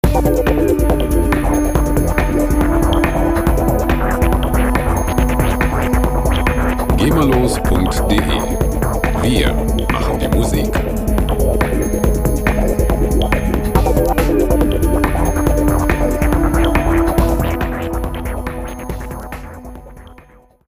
Drum 'n' Bass Loops
Musikstil: Jungle
Tempo: 144 bpm